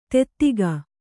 ♪ tettiga